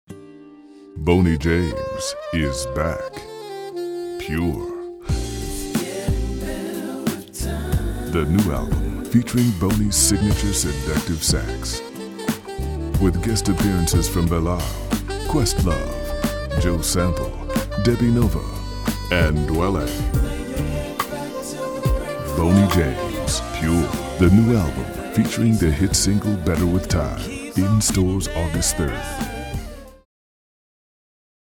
Dave Fennoy - Commercial Demo audio.mp3 Boney James